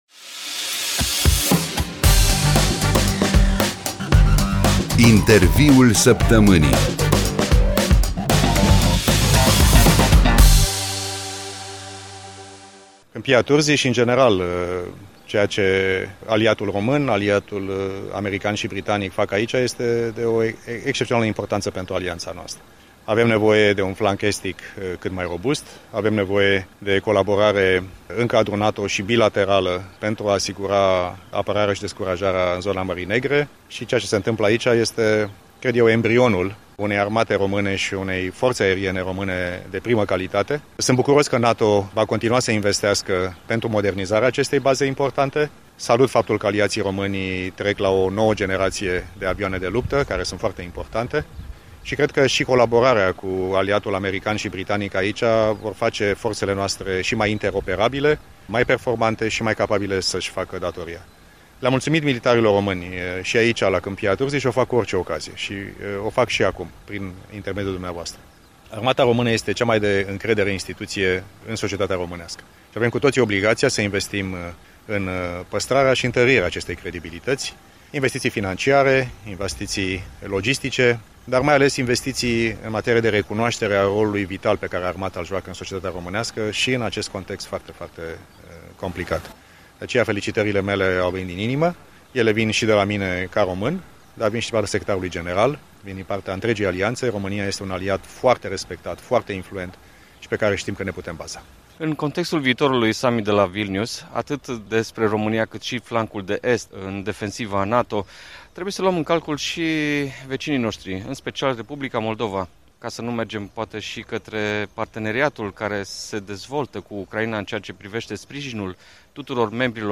Interviul săptămânii: Vizita Secretarului General Adjunct al NATO în Baza 71 Aeriană de la Câmpia Turzii